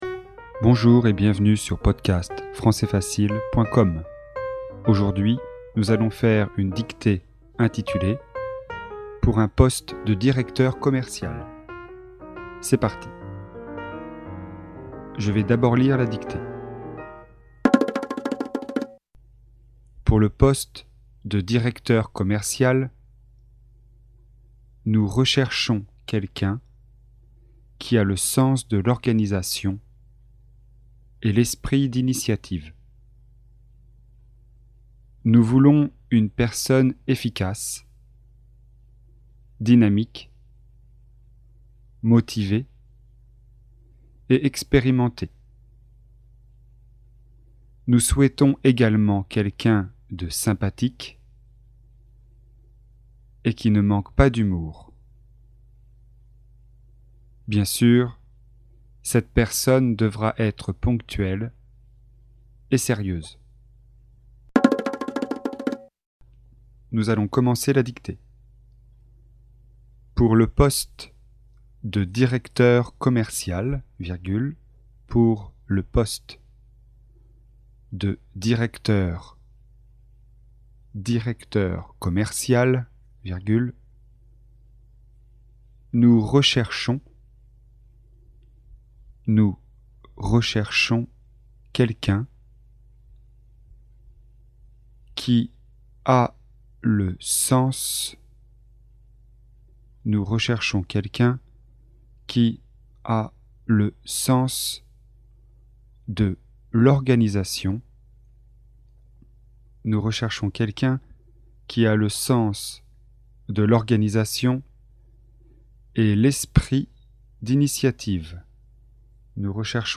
Dictée, niveau intermédiaire (A2).